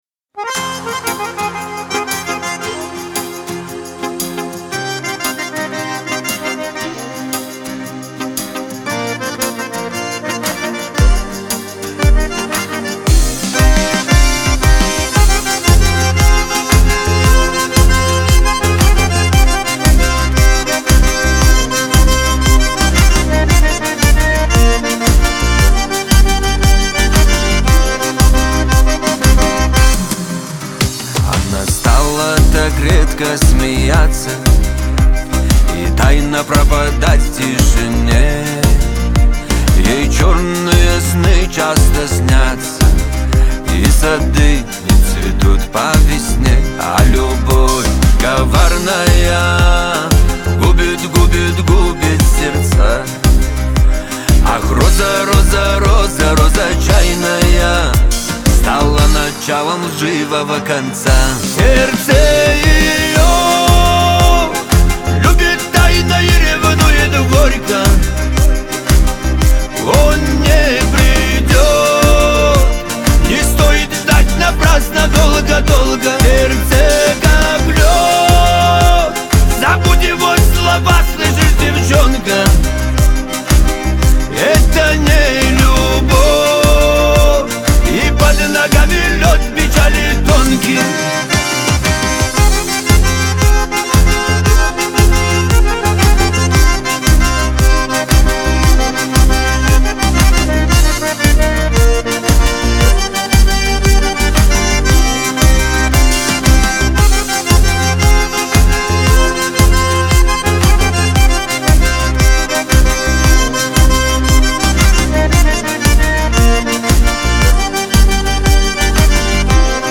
Кавказ – поп
эстрада